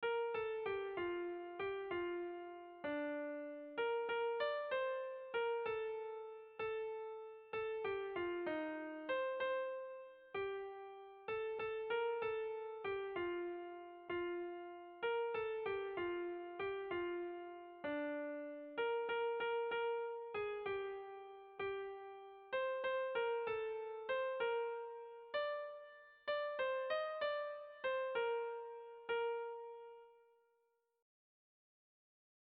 Sentimenduzkoa
Gipuzkoa < Euskal Herria
Zortziko txikia (hg) / Lau puntuko txikia (ip)
ABA2D